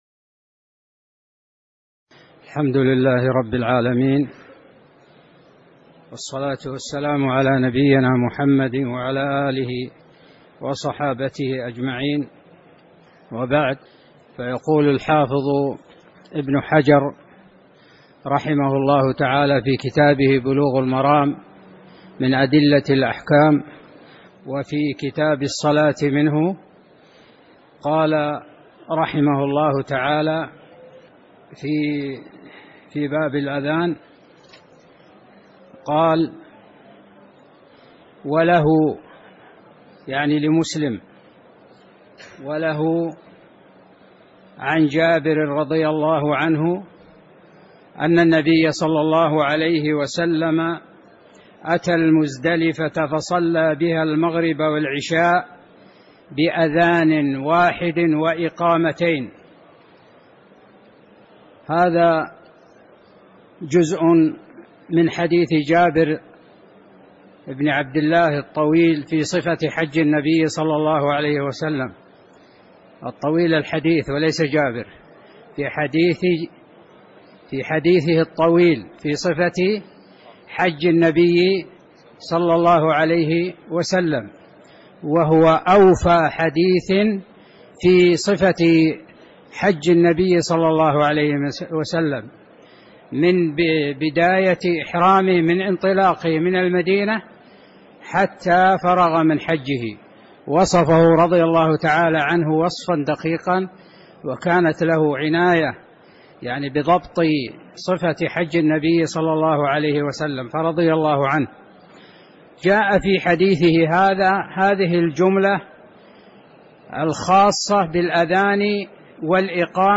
تاريخ النشر ٦ شعبان ١٤٣٨ هـ المكان: المسجد النبوي الشيخ